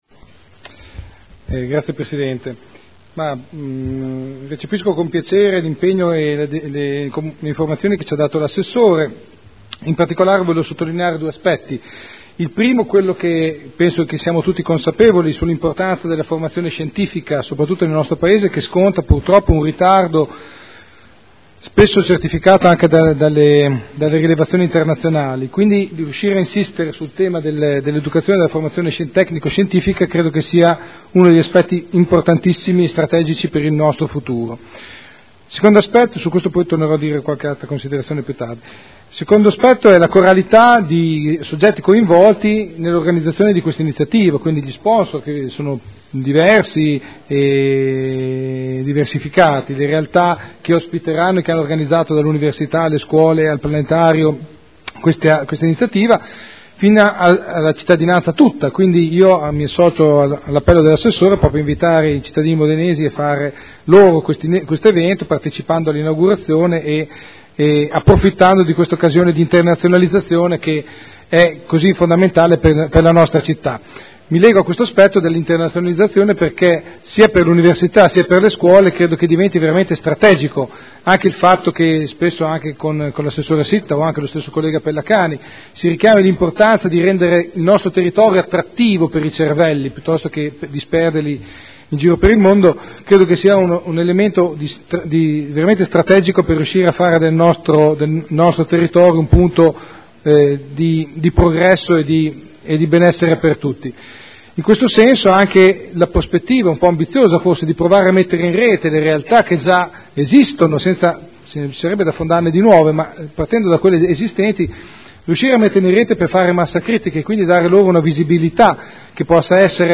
Enrico Artioli — Sito Audio Consiglio Comunale